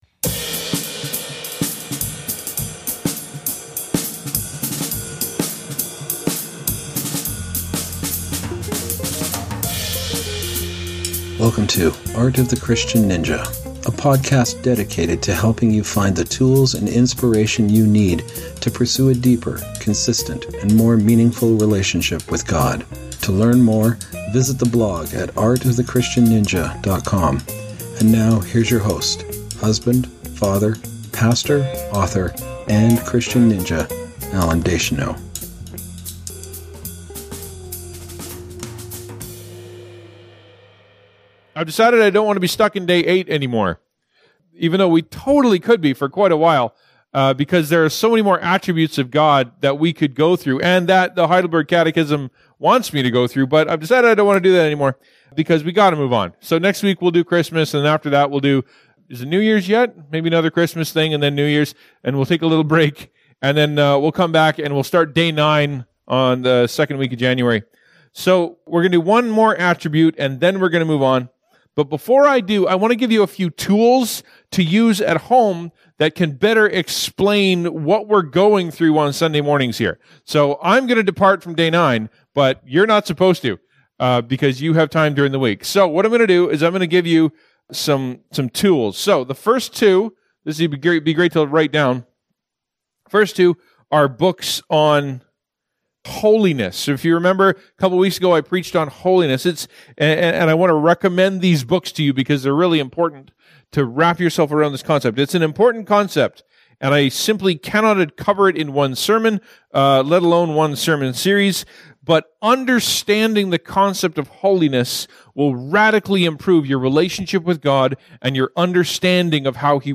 Holiness is an important concept that I simply cannot cover in one sermon, and understanding it will radically improve your relationship with God.